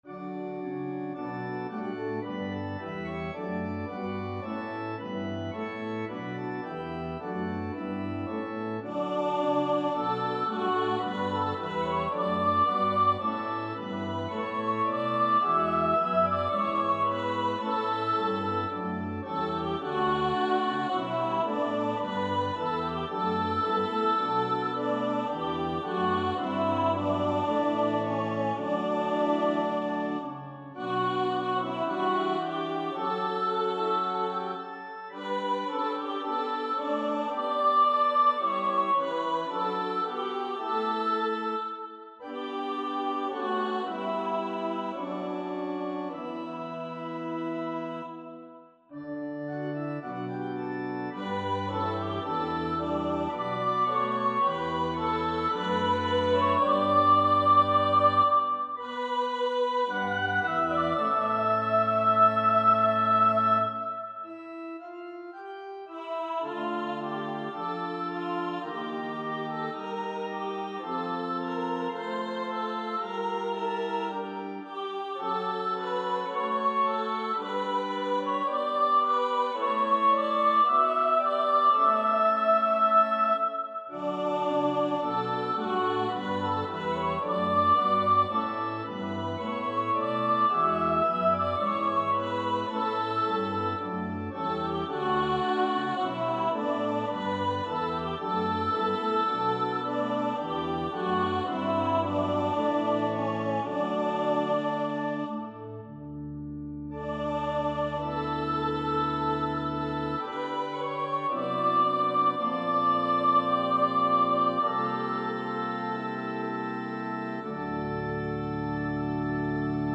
• Music Type: Choral
• Voicing: Treble Choir, Unison
• Accompaniment: Organ